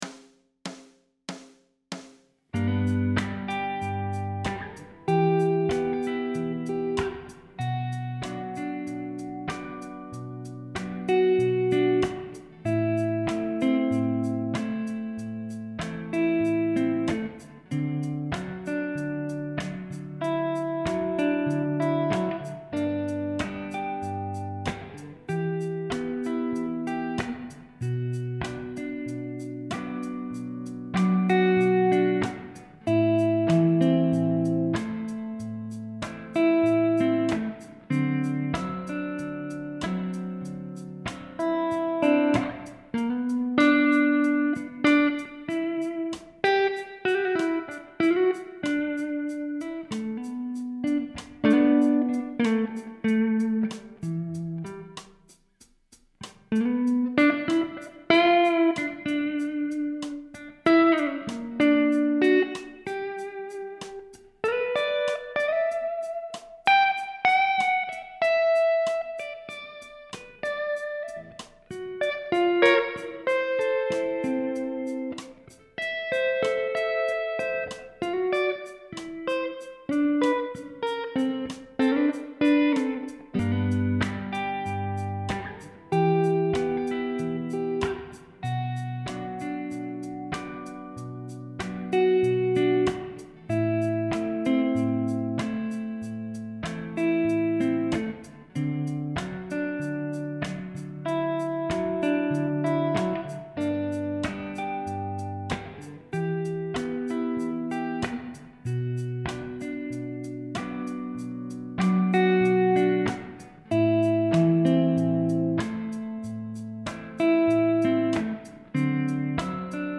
Learn to play blues guitar.
The chords in the progression are: G, Em, Bm, Am, D7 (so the progression is 1, 6, 3, 2, 5).
The percussive fingerstyle chord progression has a very integral strong melody to it… so, when I try to free-flow… it doesnt sound good because it clashes with the background melody; implying… the improvisation has to play with the integral melody; now that’s difficult!!!!